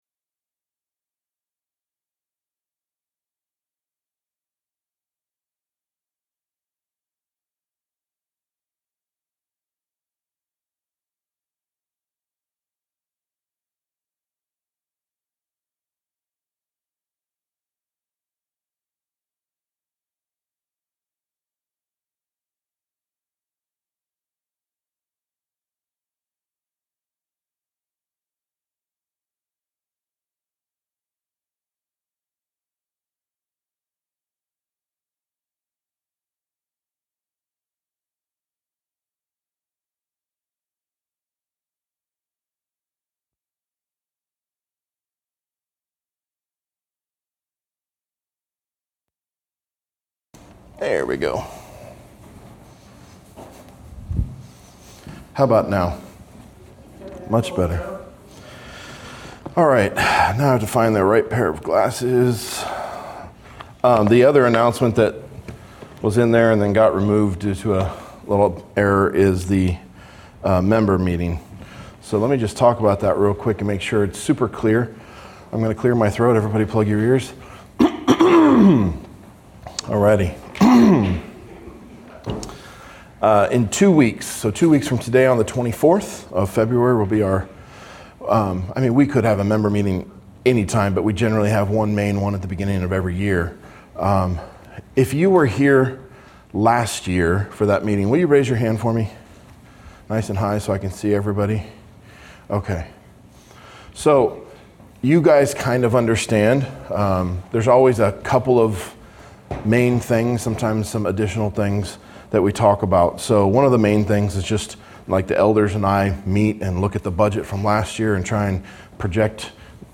Verse by verse exposition of Matthew's Gospel